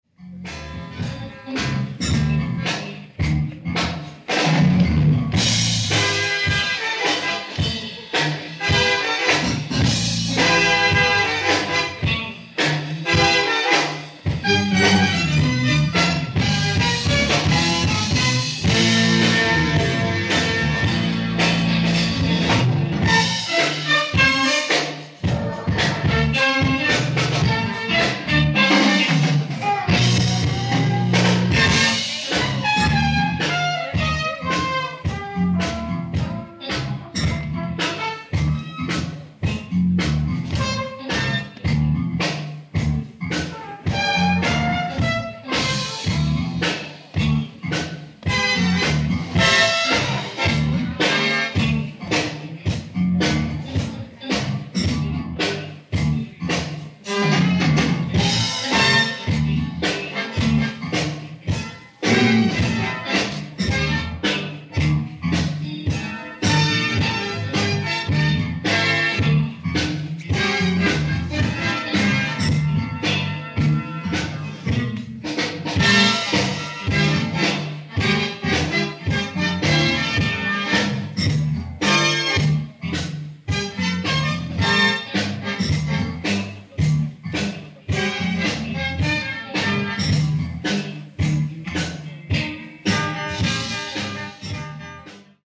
ライブ・アット・ウィルツ城、ウィルツ、ルクセンブルク
※試聴用に実際より音質を落としています。